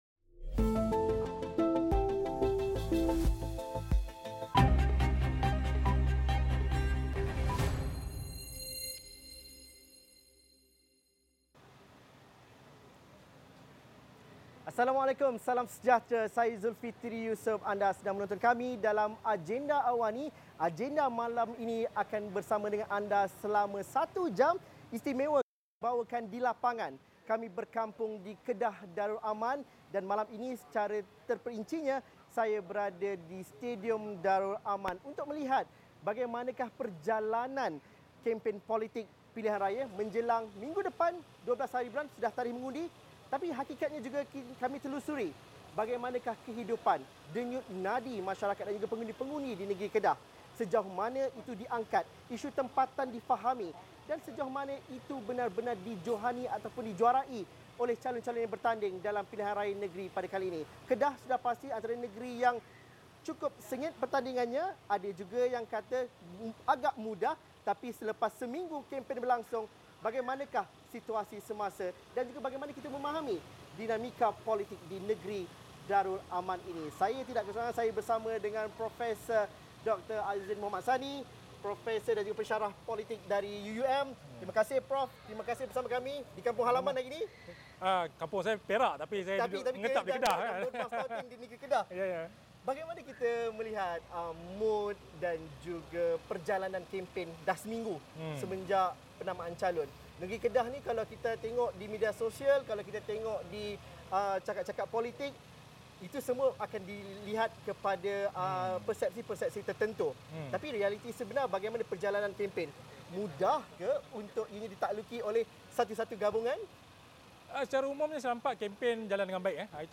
Siaran luar dari Uptown Stadium Darul Aman, Alor Setar.